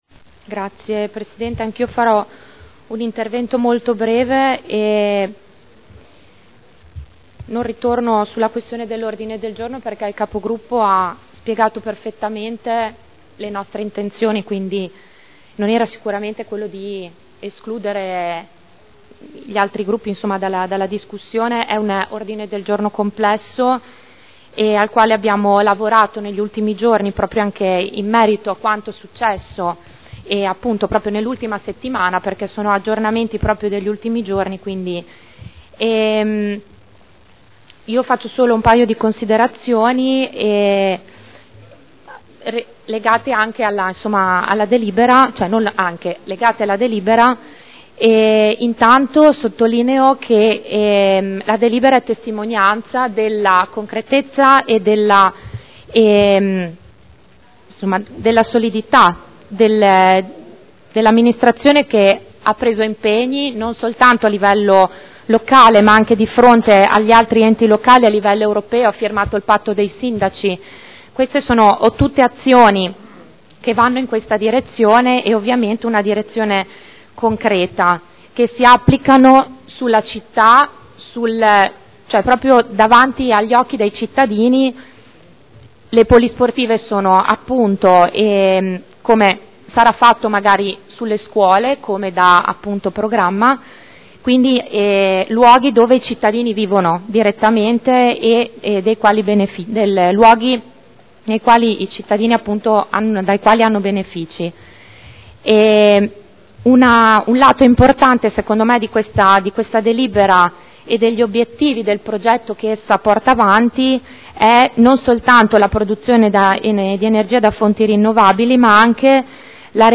Elisa Sala — Sito Audio Consiglio Comunale
Seduta del 21/03/2011. Dibattito su proposta di deliberazione: Diritto di superficie a favore della Cooperativa Spazio Unimmobiliare per gli impianti ubicati presso la Polisportiva Saliceta San Giuliano e presso la Polisportiva Gino Nasi – Autorizzazione a iscrivere ipoteca”